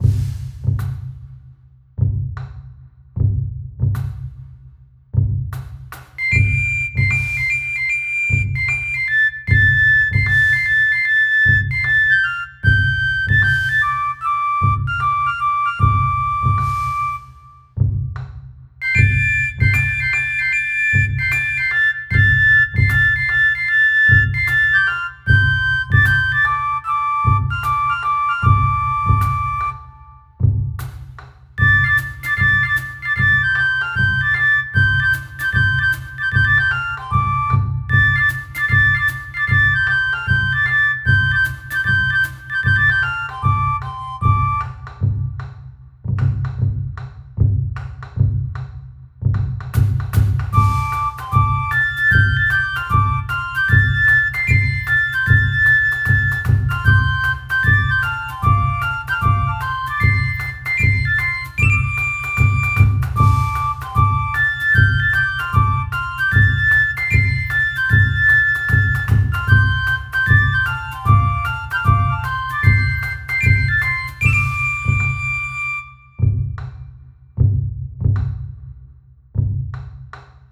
お祭りに行く途中のわくわく、ドキドキを表現した曲です
無限ループ和風